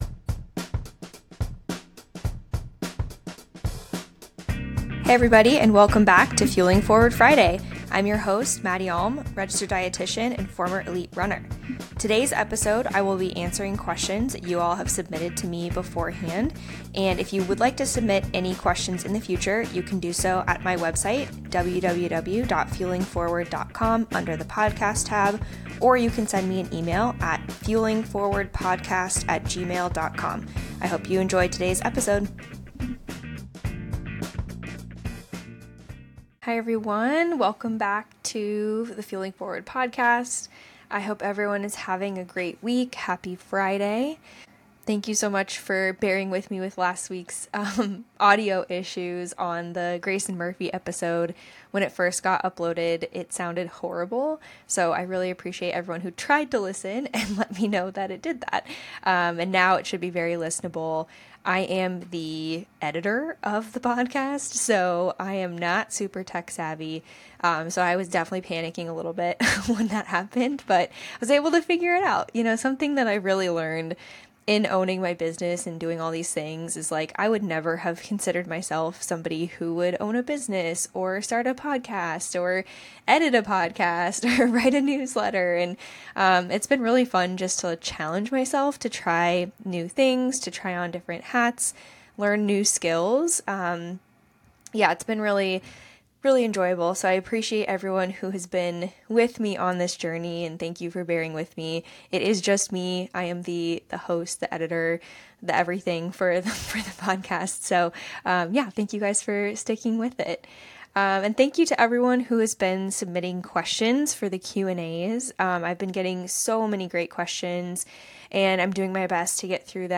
Today's episode is a Q&A where I answer questions that have been submitted by listeners.